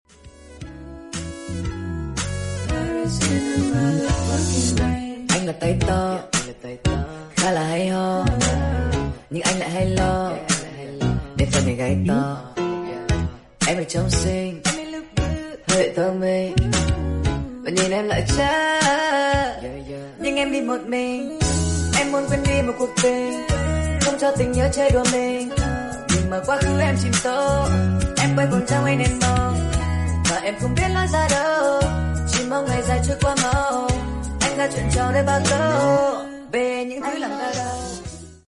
(nightcore) - speed up